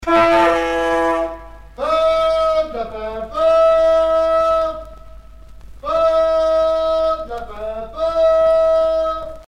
Cris de rue du marchand de peaux de lapin peaux
Région ou province Normandie
Genre brève